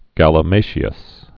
(gălə-māshē-əs, -mătē-əs)